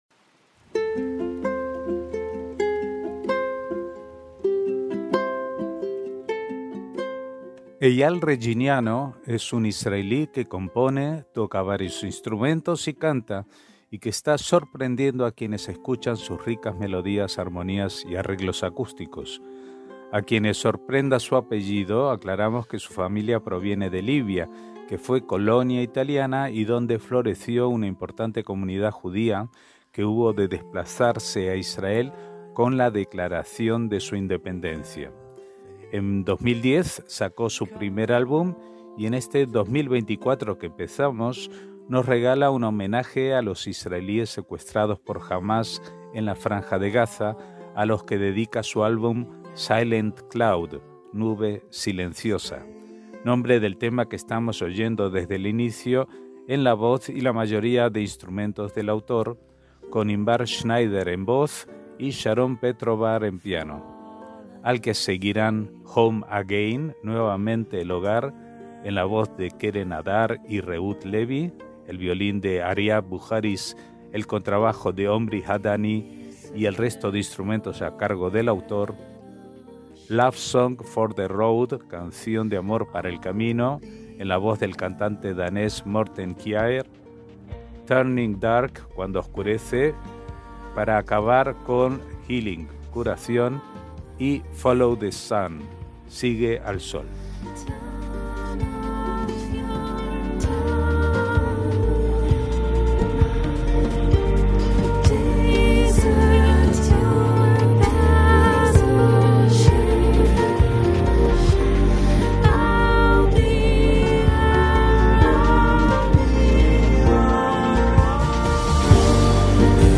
MÚSICA ISRAELÍ
piano